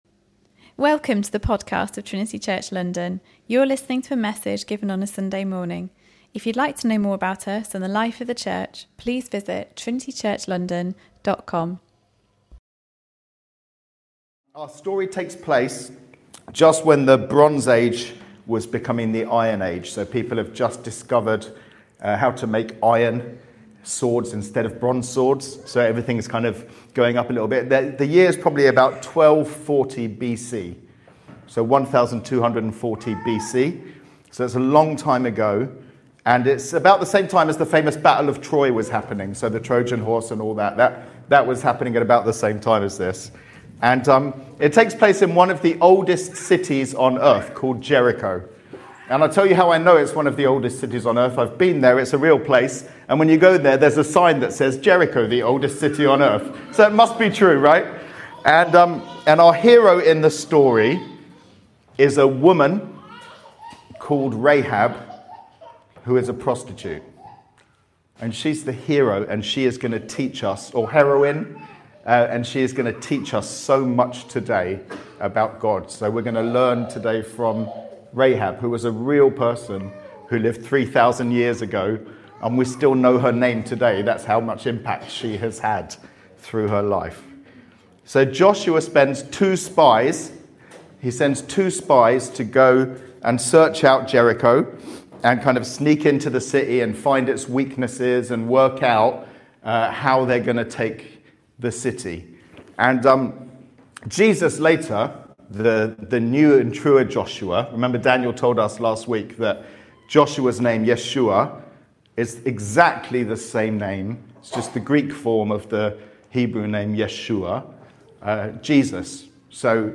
Trinity Church London Podcast Rehab: Prostitute to Princess